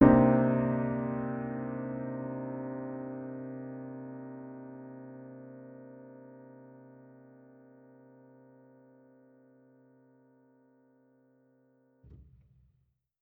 Index of /musicradar/jazz-keys-samples/Chord Hits/Acoustic Piano 2
JK_AcPiano2_Chord-Cm9.wav